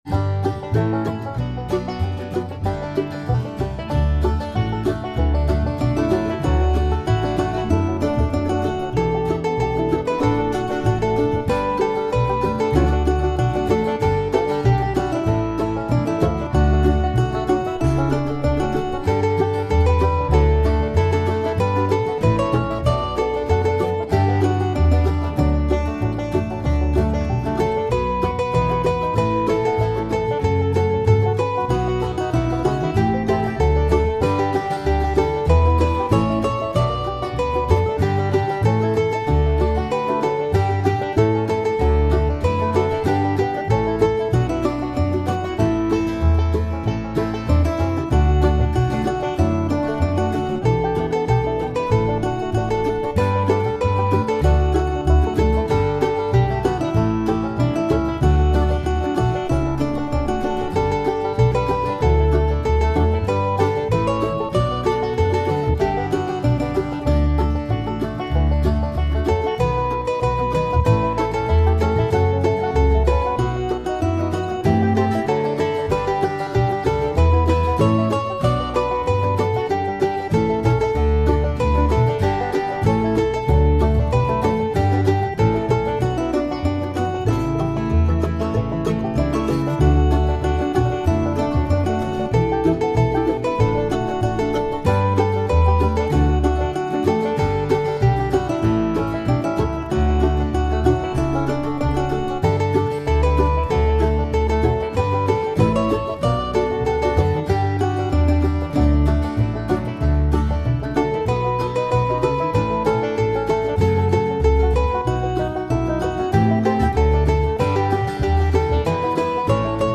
This is an enthusiastic version.
My backing is at 95 bpm.